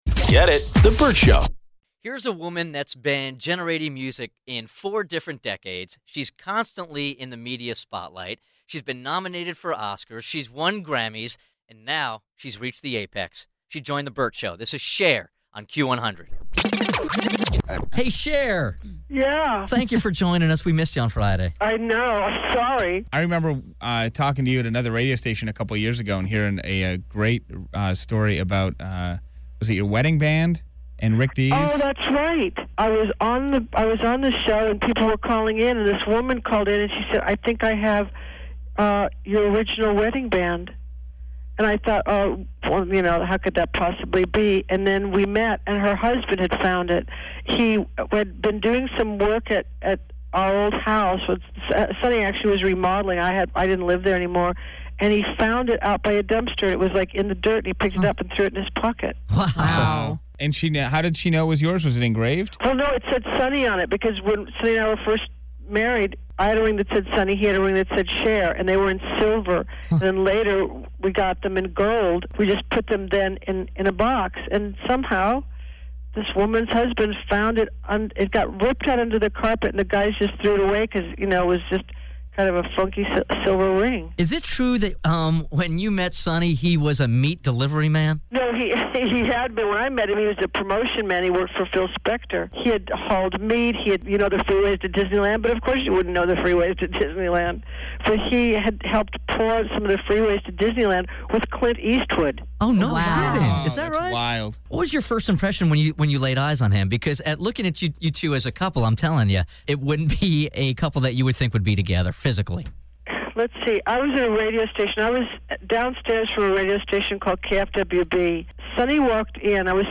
Vault: Interview Cher (Part 1)